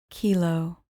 Pronounced: key-loh